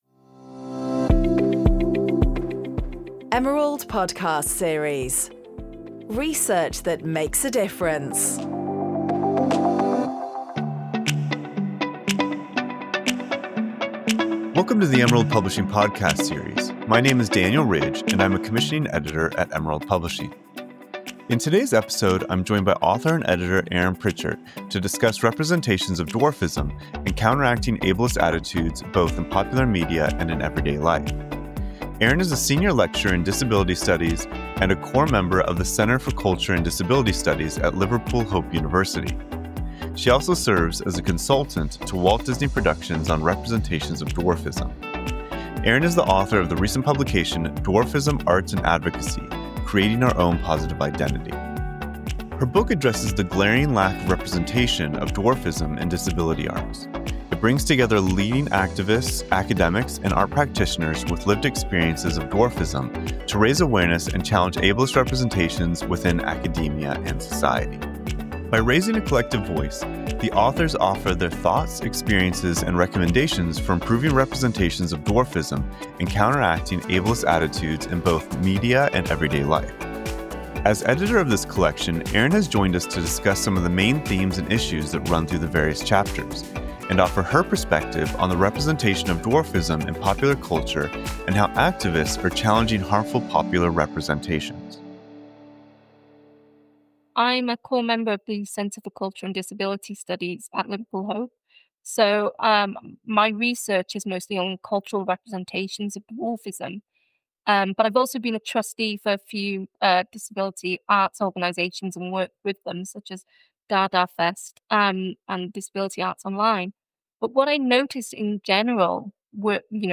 Join our hosts as they talk to experts using their research to create real impact in society. In each episode we explore the role research plays in our modern world, and ask how it can contribute to solving the complex environmental, economic, social and political challenges facing our planet.